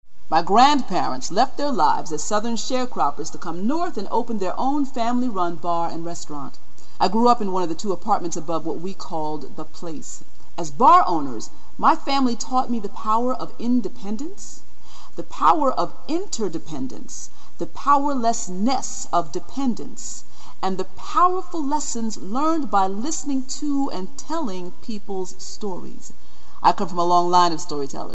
34 Years of Tuesday Night Storytelling